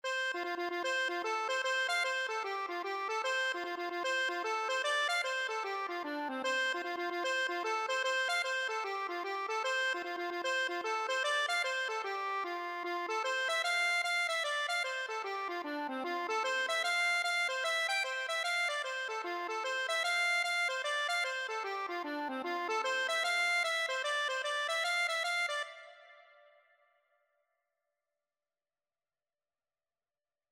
F major (Sounding Pitch) (View more F major Music for Accordion )
4/4 (View more 4/4 Music)
Accordion  (View more Intermediate Accordion Music)
Traditional (View more Traditional Accordion Music)
Ireland    reels